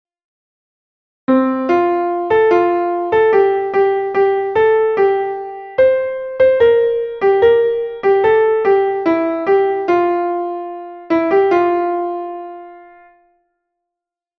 Entoación a capella
Melodía 2/4 en Fa M